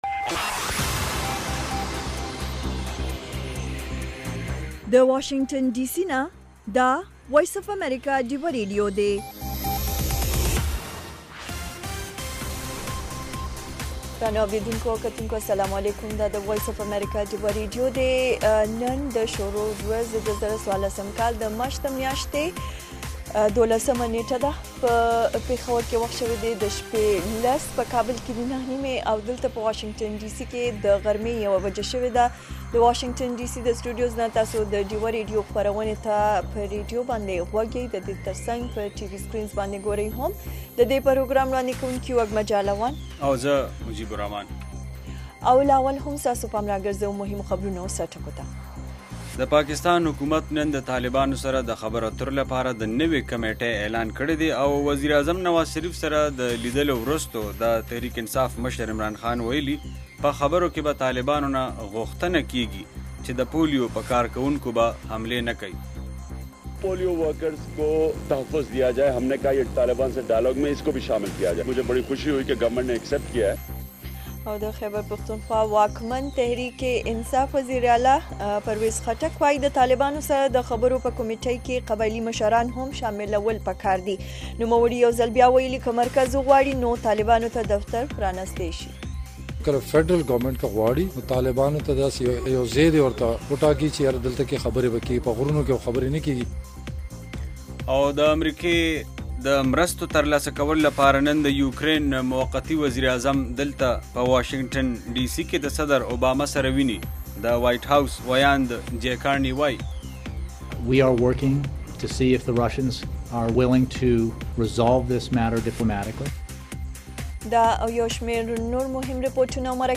خبرونه - 1700
د وی او اې ډيوه راډيو ماښامنۍ خبرونه چالان کړئ اؤ د ورځې د مهمو تازه خبرونو سرليکونه واورئ. په دغه خبرونو کې د نړيوالو، سيمه ايزو اؤمقامى خبرونو هغه مهم اړخونه چې سيمې اؤ پښتنې ټولنې پورې اړه لري شامل دي. دخبرونو په دې جامع وخت کې دسياسياتو، اقتصاد، هنر ، ټنګ ټکور، روغتيا، موسم اؤ لوبو په حقله ځانګړې ورځنۍ فيچرې شاملې دي.